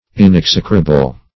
Inexecrable \In*ex"e*cra*ble\, a. That can not be execrated enough.